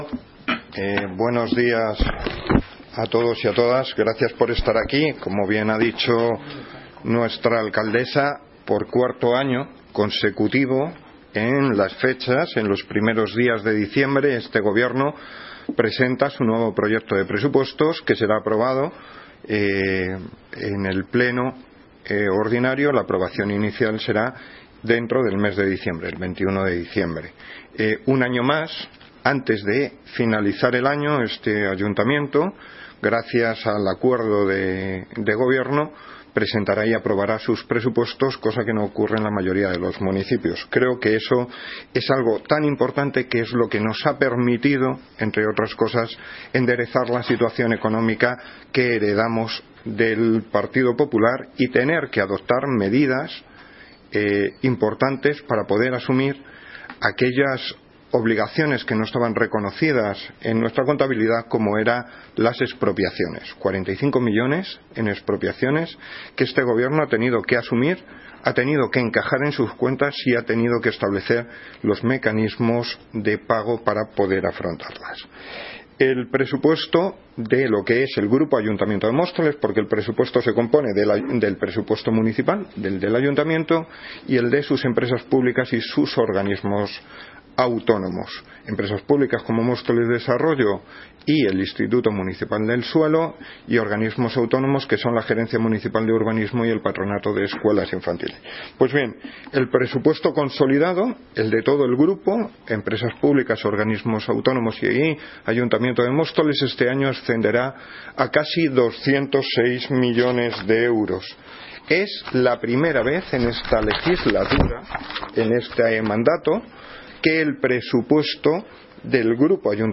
Audio - Francisco Javier Gómez (Concejal de Hacienda, Transporte y Movilidad) Sobre Presupuestos 2019